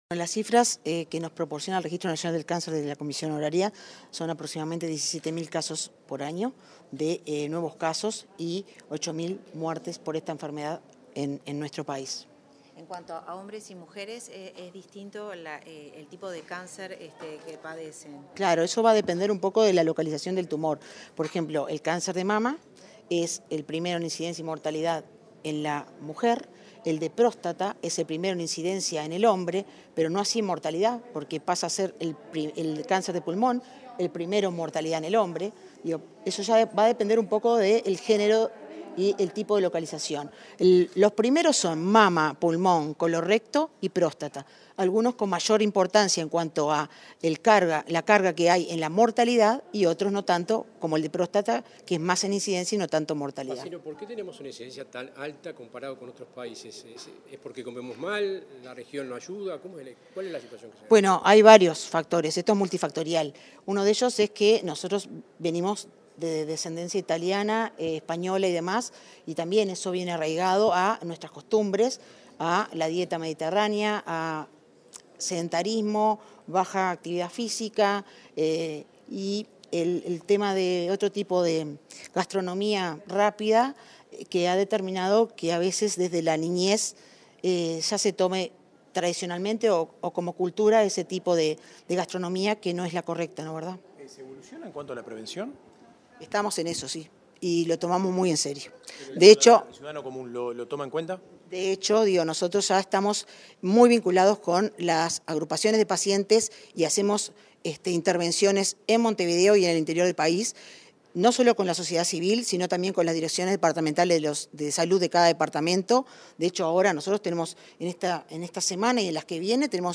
Declaraciones de la directora de Pronaccan, Marisa Fazzino